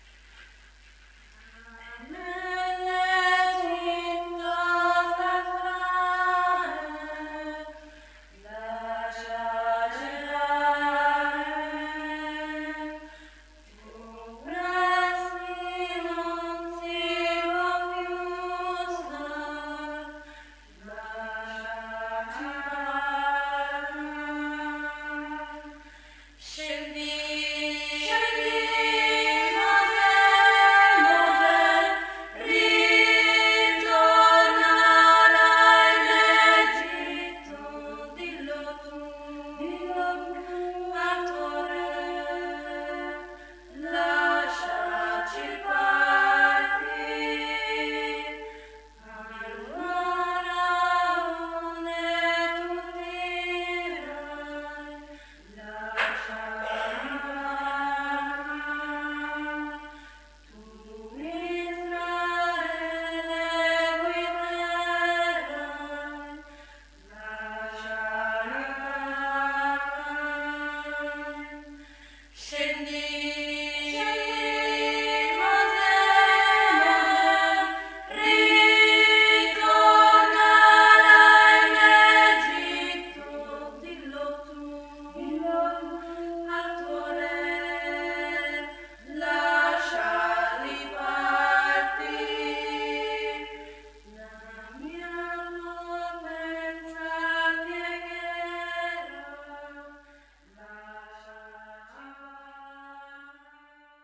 Coretto parrocchiale "Les Mariutines" di Tomba
SCENDI MOSE' - Spiritual